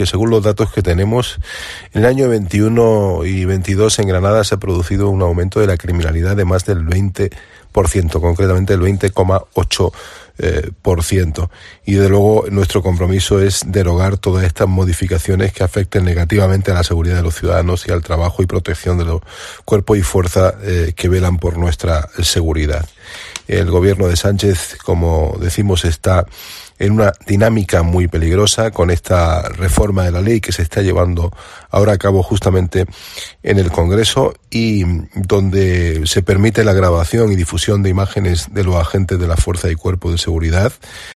Carlos Rojas, diputado del PP